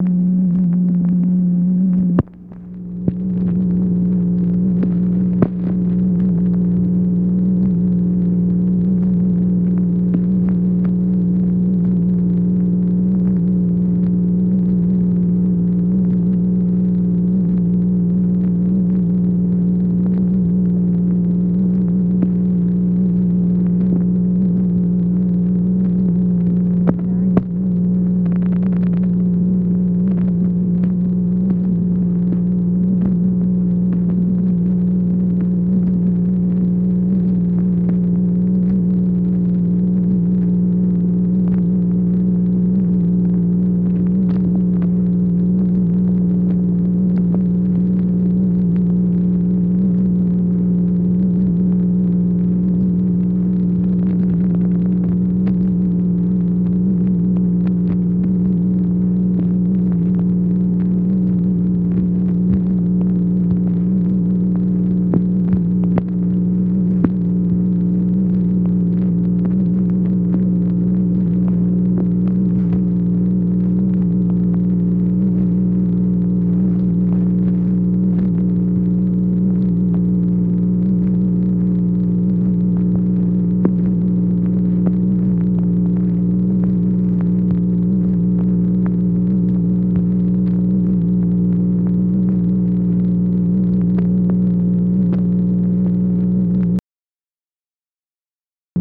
MACHINE NOISE, February 11, 1964
Secret White House Tapes | Lyndon B. Johnson Presidency